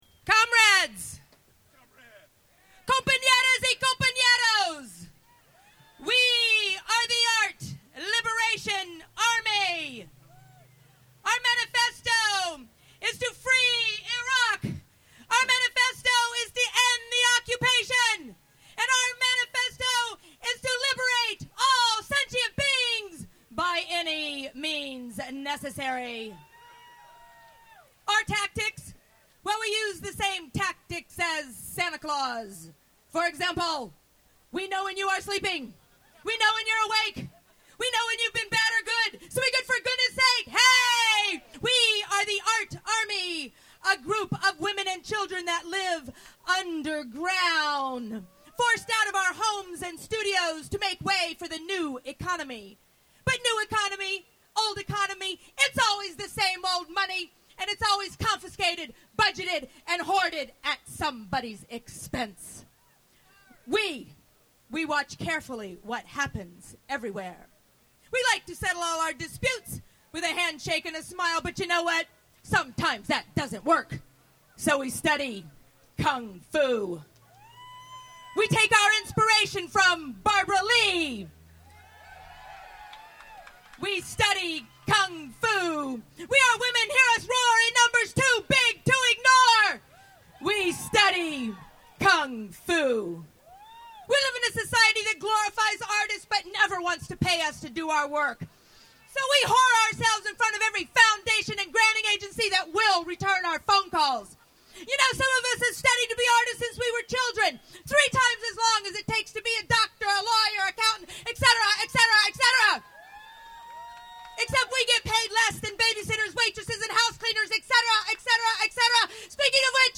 The Dance Brigade performs at anti-war demo
The Dance Brigade performed at the 25 October 2003 anti-war demonstration in San Francisco.